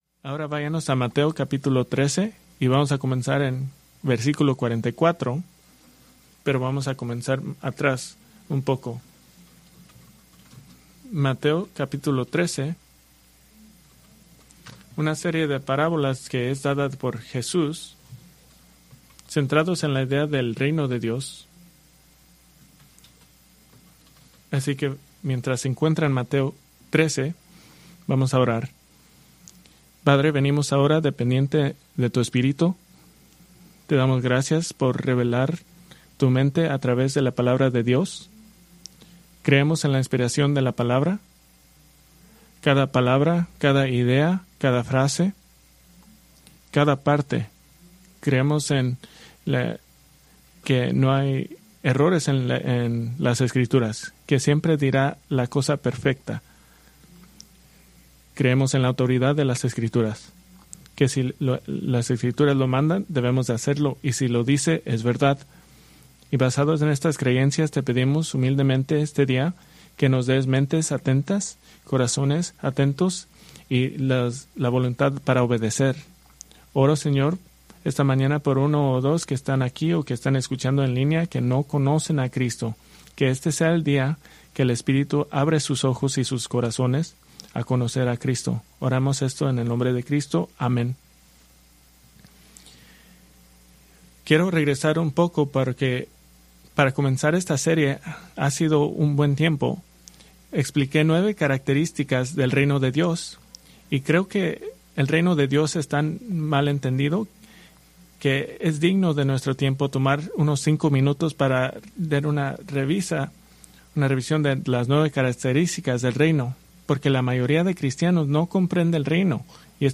Preached March 22, 2026 from Mateo 13:44-46